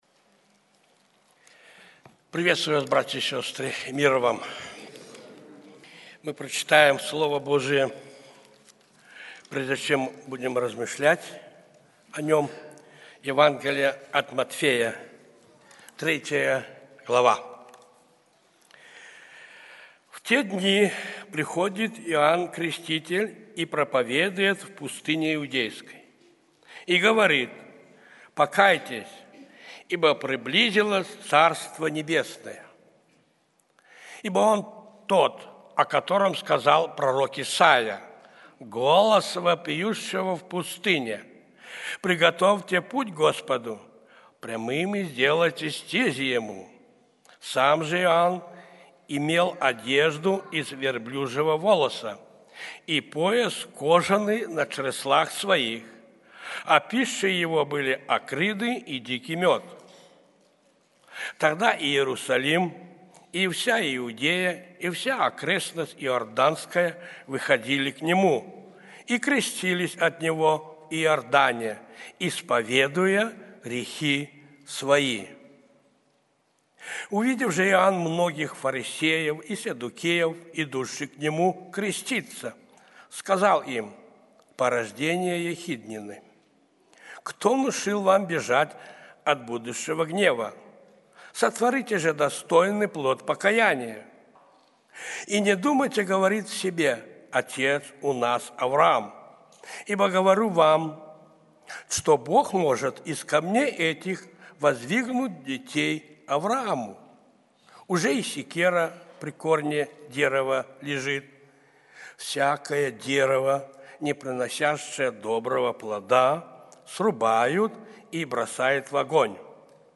Церковь евангельских христиан баптистов в городе Слуцке